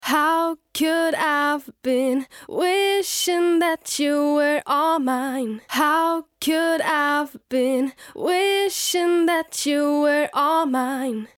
Für dieses erste Reverb auf dem Vocal wählen wir eine kurze Hallfahne von ca. 50 Millisekunden.
Im Klangbeispiel solltest Du hören, dass die Stimme mit dem Effekt etwas fetter und facettenreicher klingt und zudem eine neue Textur hat, die vorher noch nicht da war. Hinweis: Im ersten Teil des Klangbeispiels ist unser Effekt aus. Im zweiten Teil ist er an.
#1 Reverb Klangbeispiel A/B